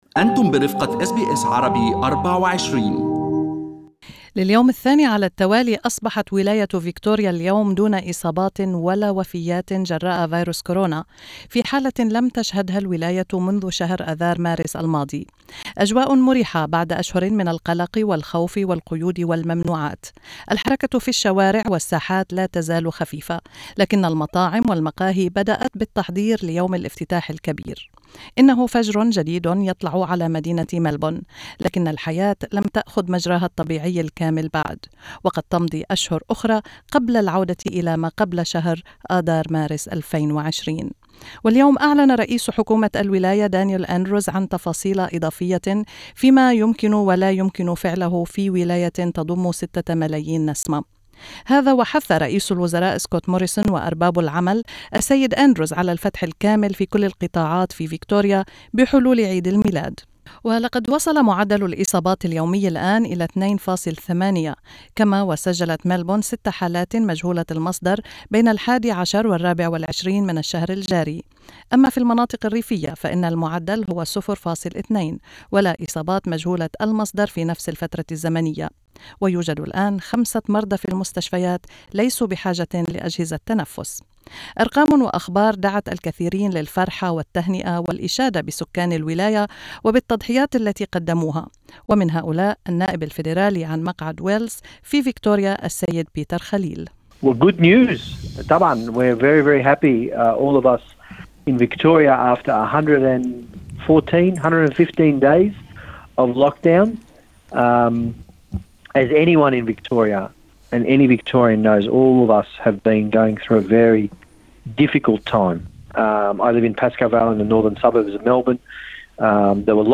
استمعوا إلى لقاء مع النائب بيتر خليل في المدونة الصوتية في أعلى الصفحة.